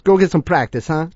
gutterball-3/Gutterball 3/Commentators/Louie/l_gogetsomepractice.wav at 94cfafb36f1f1465e5e614fe6ed6f96a945e2483